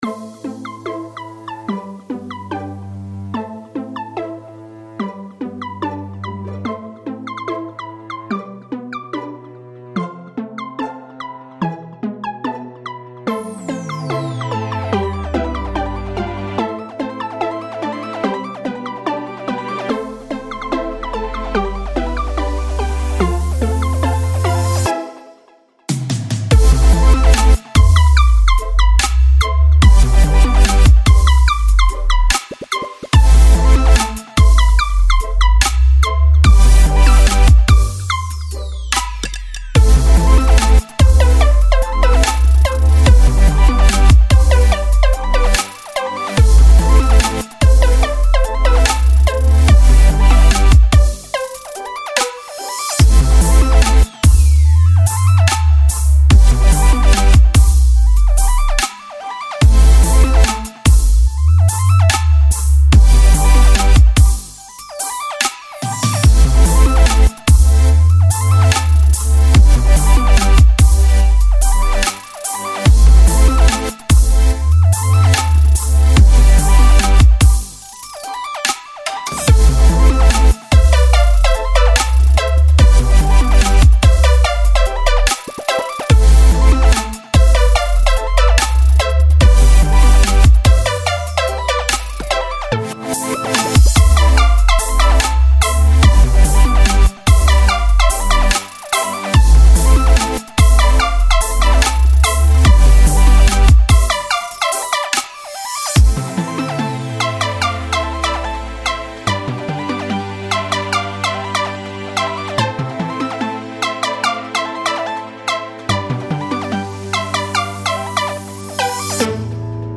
Žánr: Electro/Dance
Genres: Dubstep, Music, Electronic, Dance